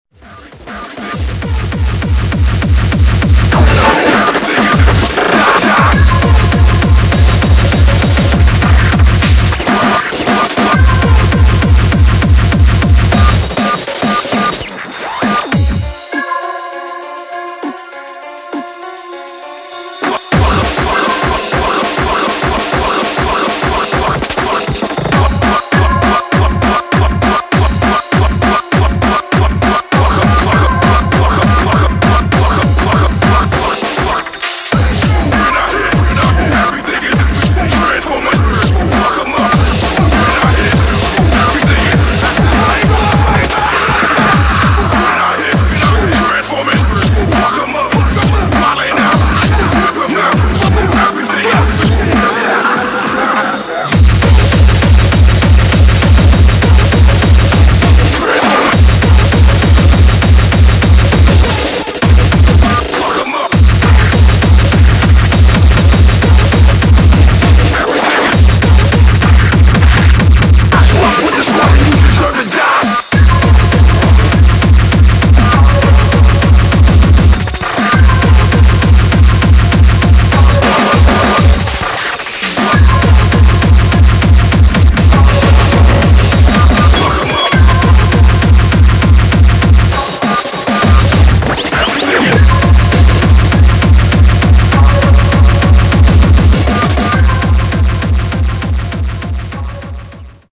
[ HARCORE ]